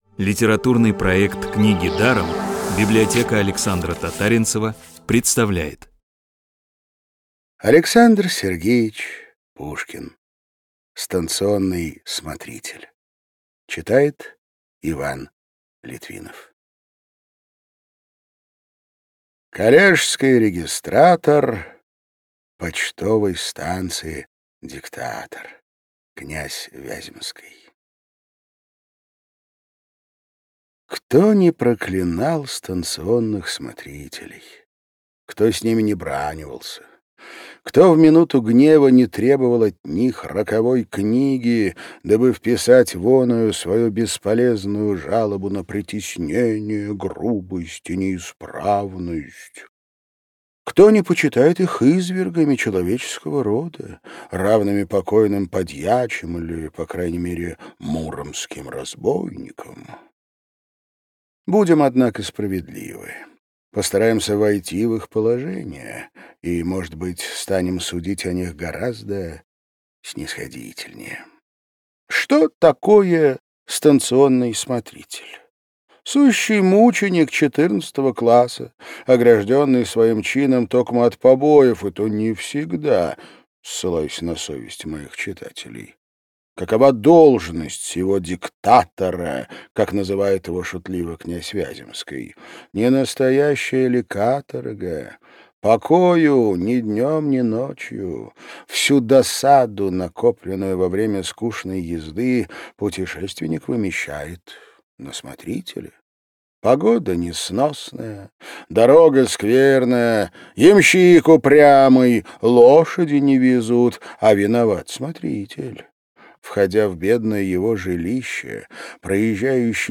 «Книги даром» подготовили для вас аудиоверсию повести «Станционный смотритель». Классическую литературу в озвучке «Рексквер» легко слушать благодаря профессиональной актерской игре и качественному звуку.